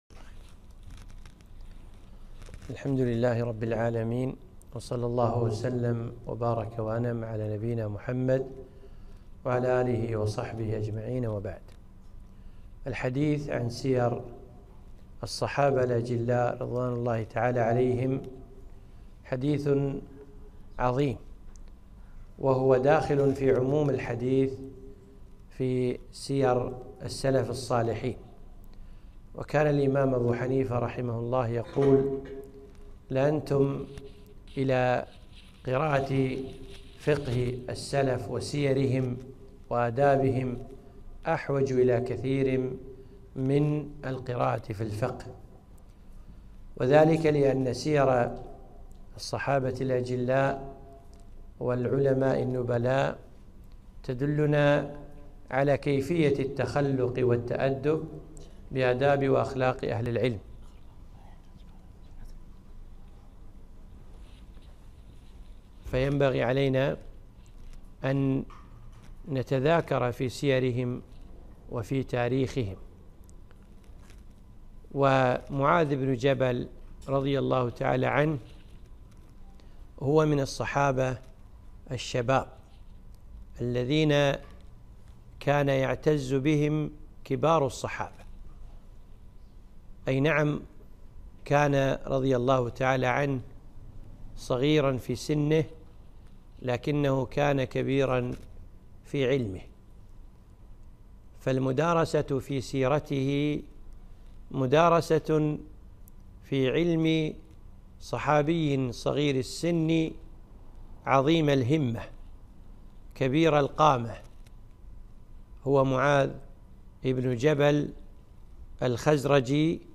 محاضرة - مقتطفات من سيرة معاذ بن جبل رضي الله عنه